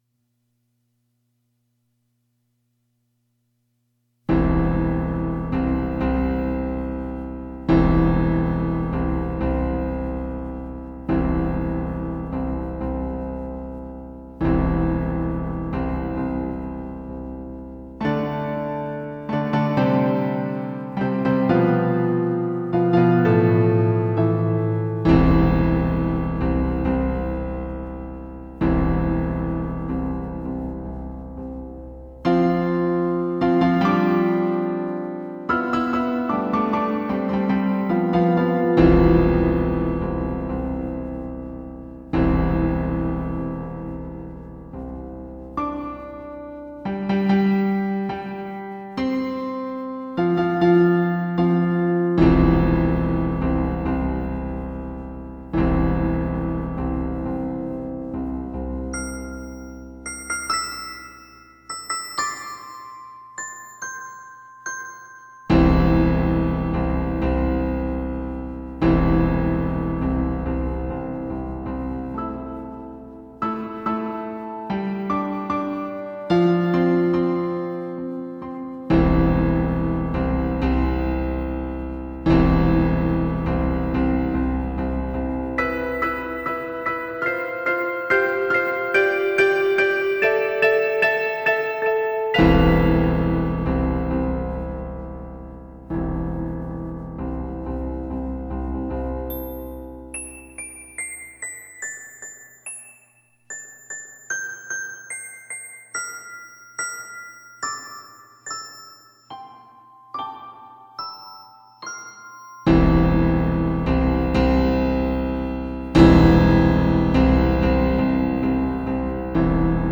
piano
Piano Piece.